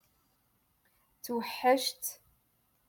Moroccan Dialect - Rotation Two- Lesson Forty Eight